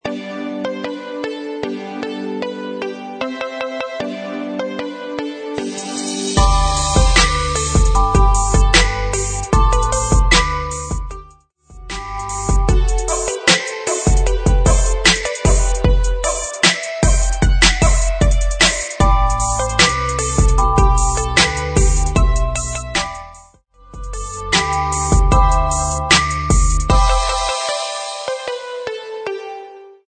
Music Beds
Christmas